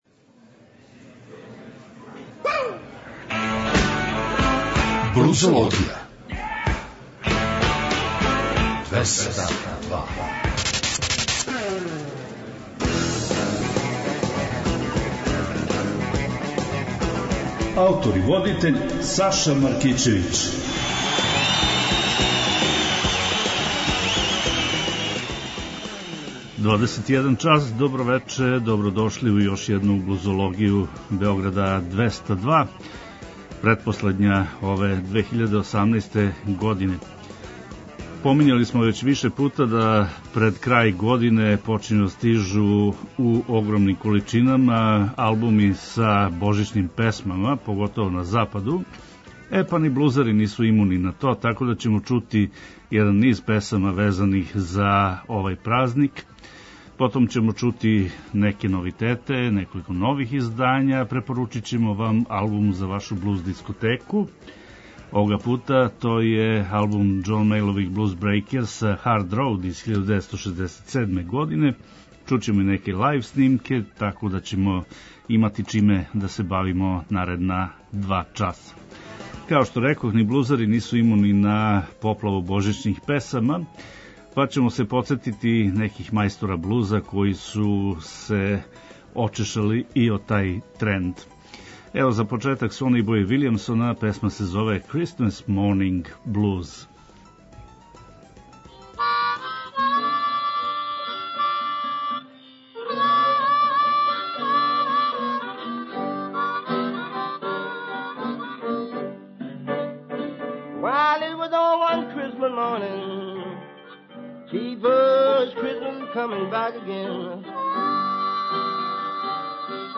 Поштујући захтеве многих слушалаца, Београд 202 је од 10. јануара 2015. покренуо нову музичку емисију под називом „Блузологија“.
Нудимо вам избор нових музичких издања из овог жанра, али не заборављамо ни пионире који су својим радом допринели развоју блуза и инспирисали младе музичаре широм света да се заинтересују и определе за професионалну каријеру у овој области.